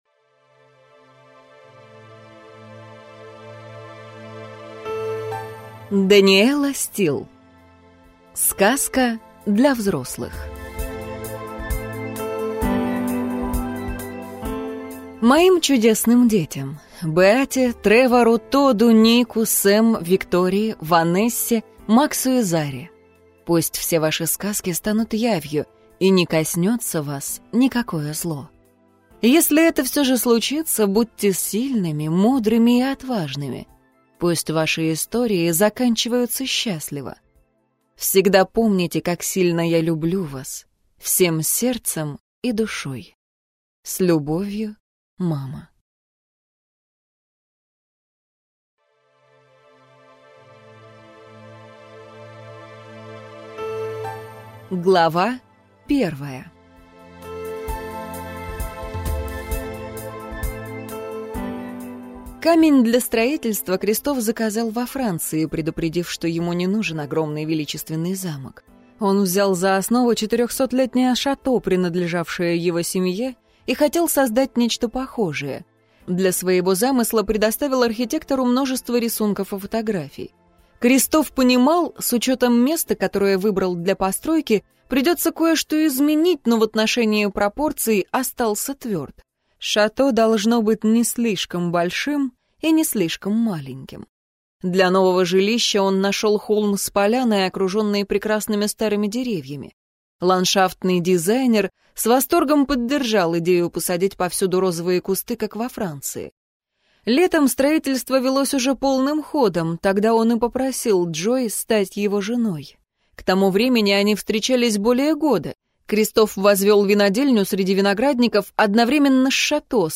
Аудиокнига Сказка для взрослых | Библиотека аудиокниг